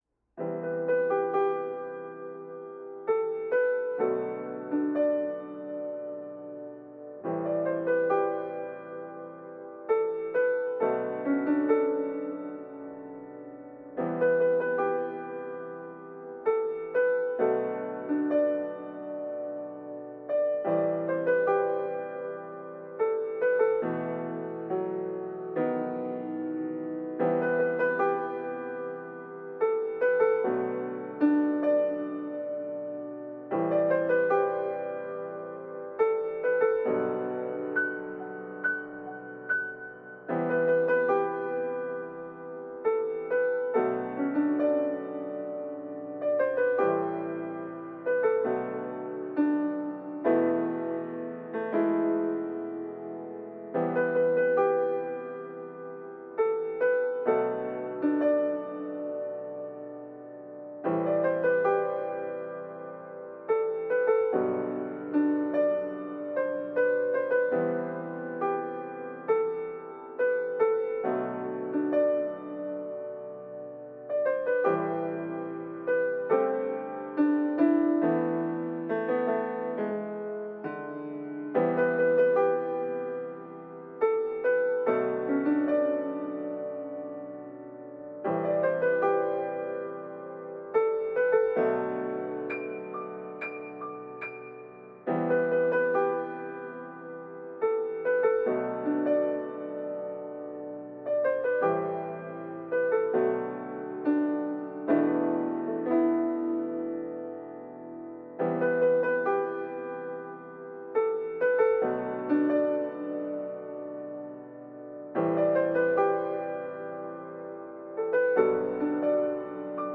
キリスト教の賛美歌のひとつ